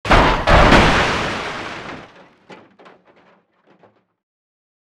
crash.wav